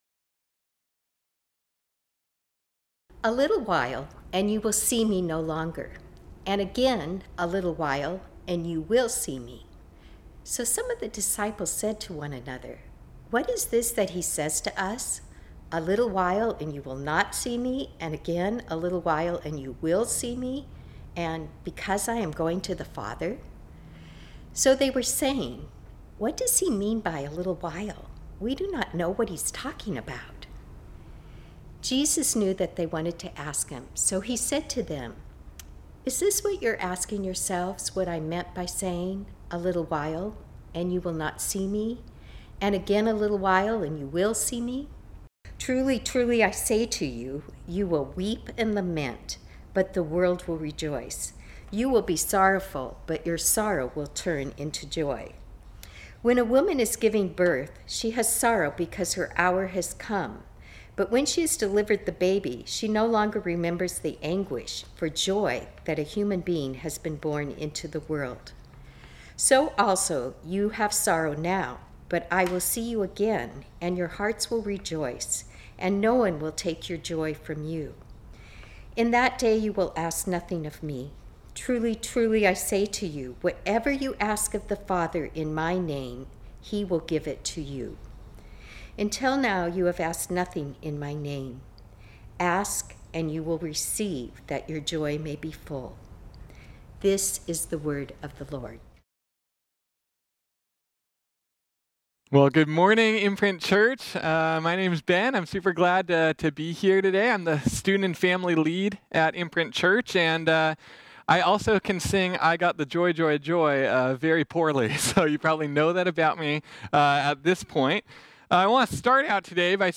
This sermon was originally preached on Sunday, June 21, 2020.